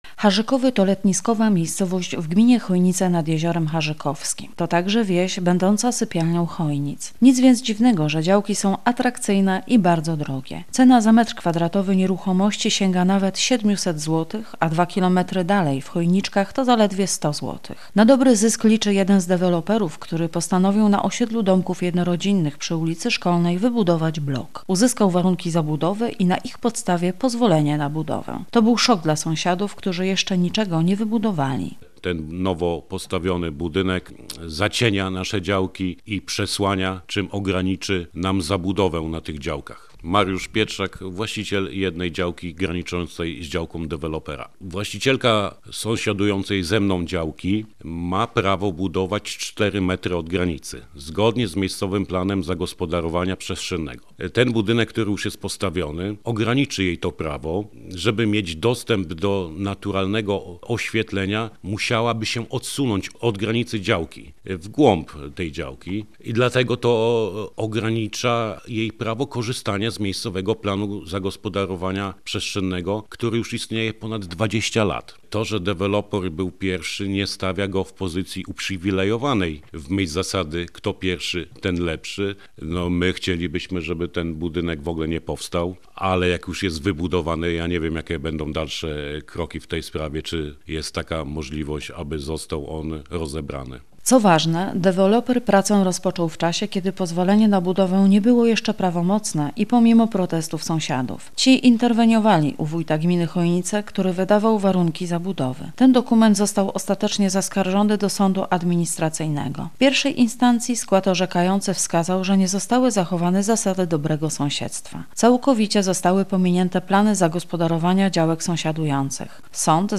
Posłuchaj materiału naszej reporterki: